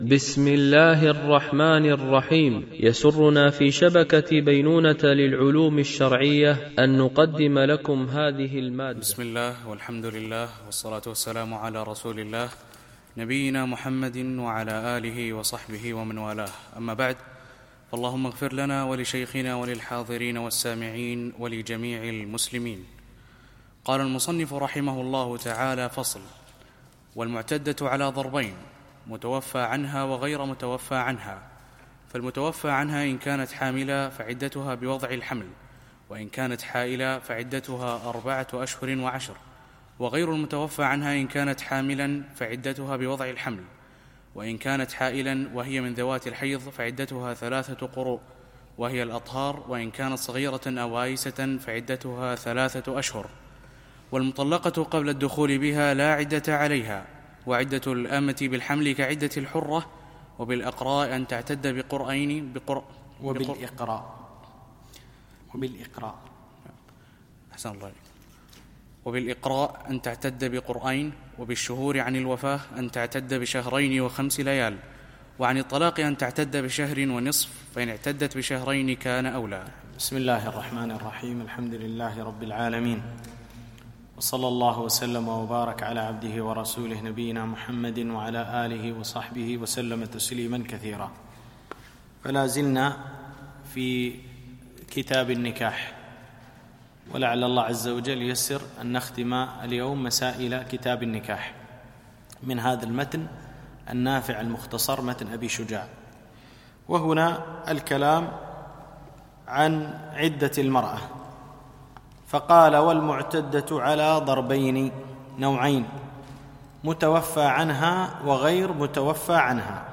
شرح متن أبي شجاع في الفقه الشافعي ـ الدرس 36